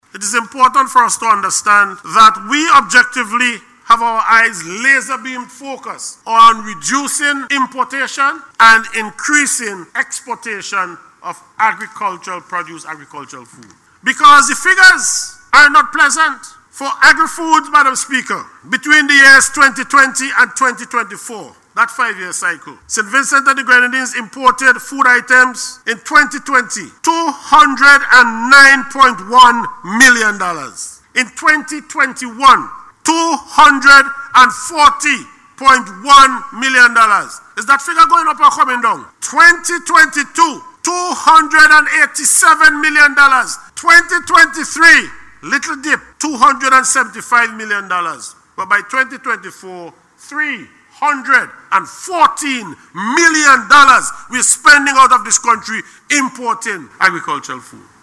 He made this statement during his contribution to the budget debate.